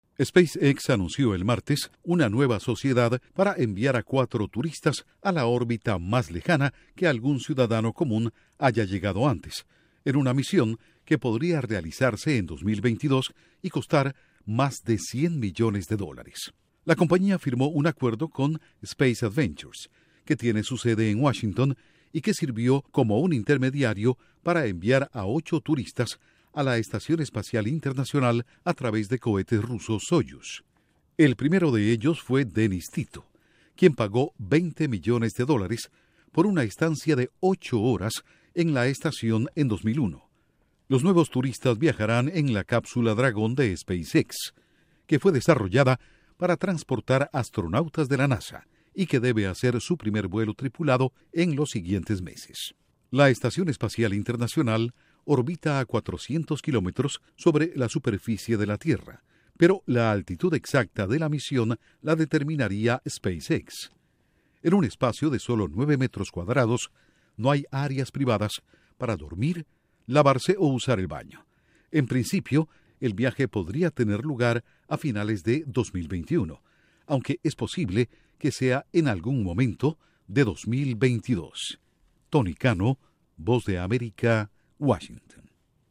SpaceX anuncia nuevo proyecto para enviar turistas a órbita lejana. Informa desde la Voz de América en Washington